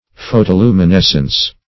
Search Result for " photoluminescence" : The Collaborative International Dictionary of English v.0.48: Photoluminescence \Pho`to*lu`mi*nes"cence\, a. [Photo- + luminescence.]